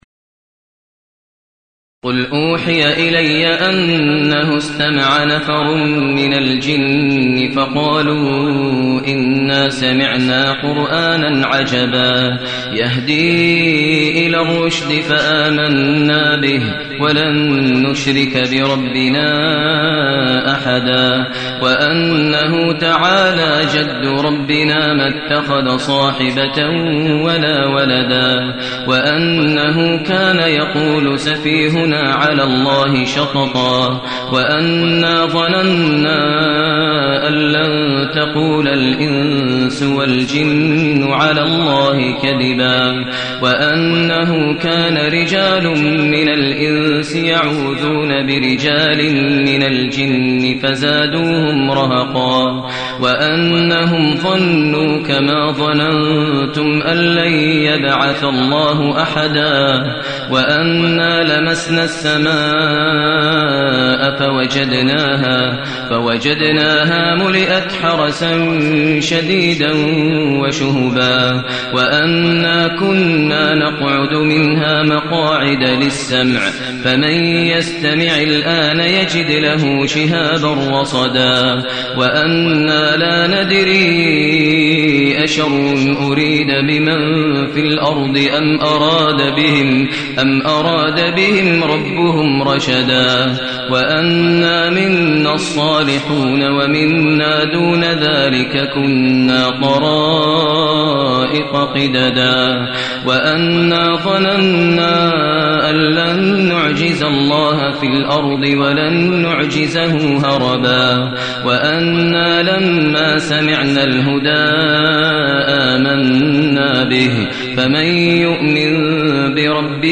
المكان: المسجد النبوي الشيخ: فضيلة الشيخ ماهر المعيقلي فضيلة الشيخ ماهر المعيقلي الجن